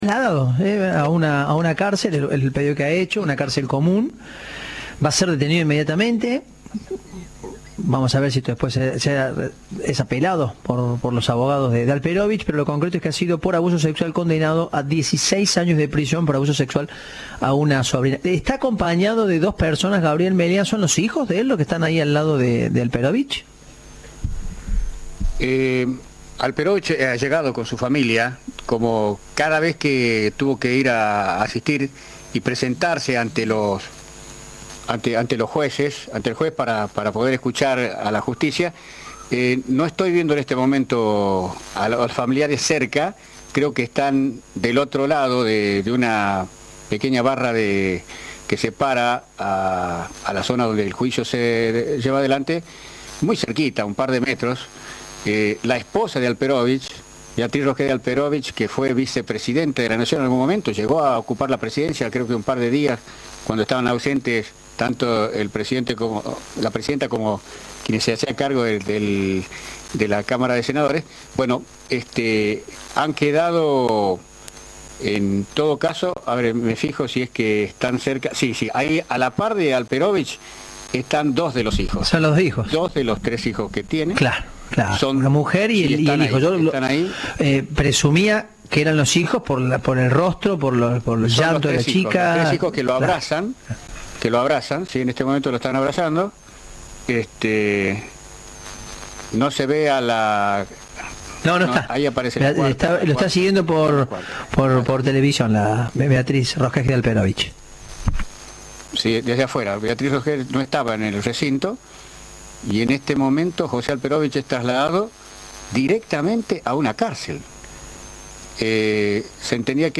El audio de la lectura del fallo contra José Alperovich por abuso sexual